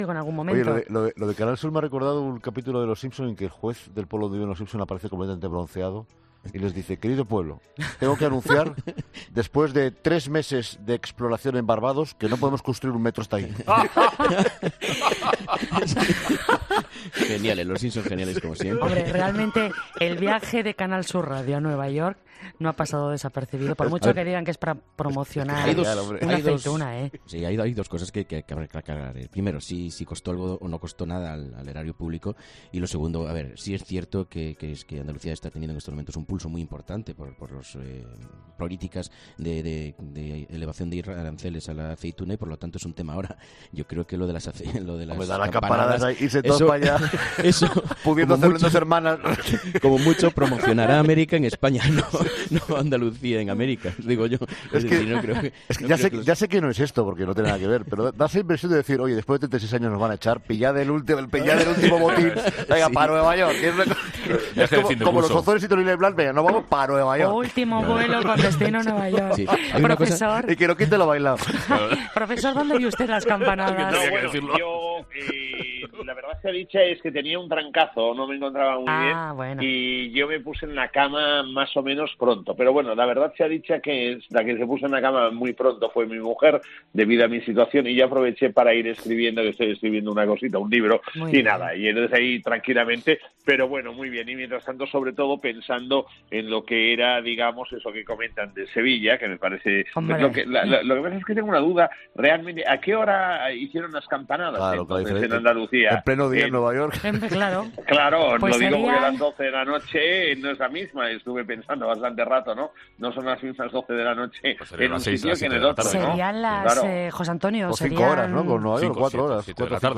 En 'Herrera en COPE', en el primer café de la mañana, los tertulianos no dudaban en comentar ambas polémicas.